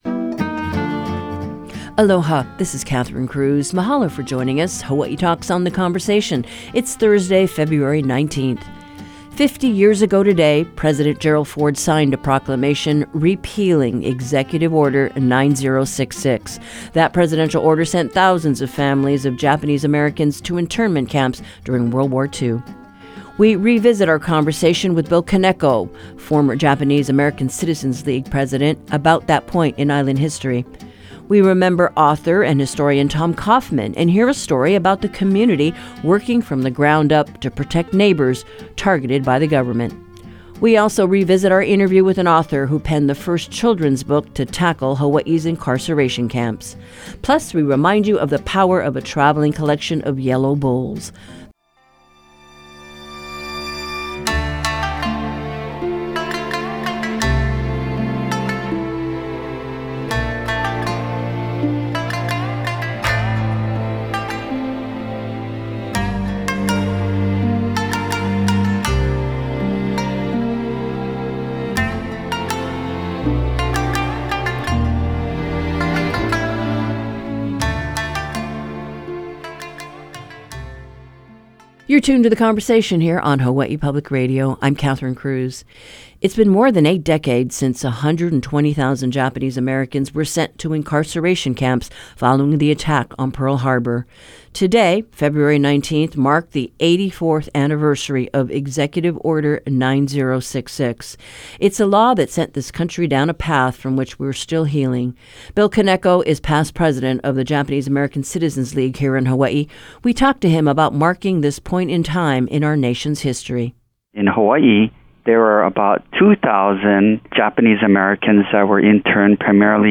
The Conversation is rebroadcasting interviews that discuss the experience of Japanese Americans on the continent and here in Hawaiʻi during internment.